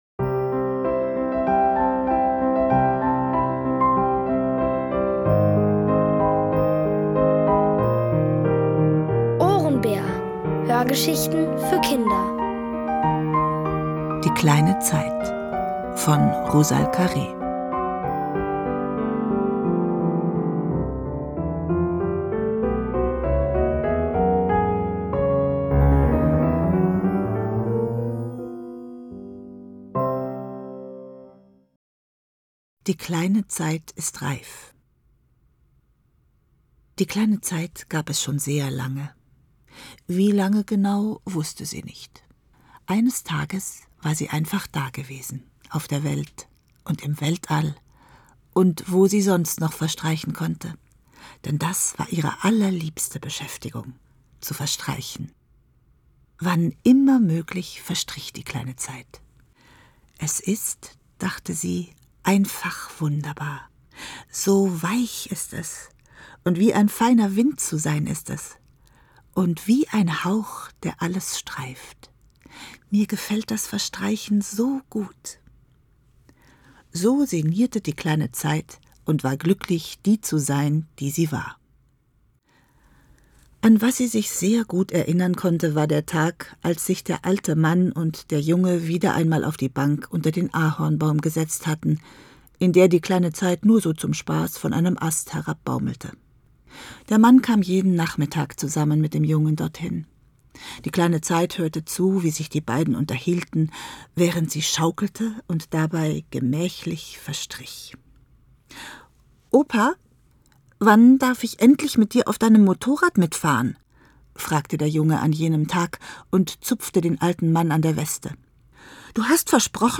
Es liest: Martina Gedeck.